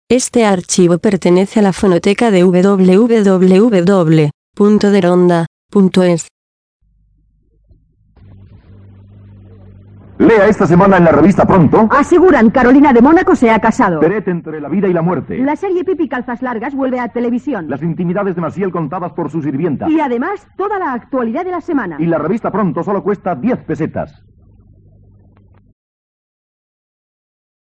En el siguiente corte escuchamos un anuncio de la revista Pronto emitido en Radio Romda en los años 80.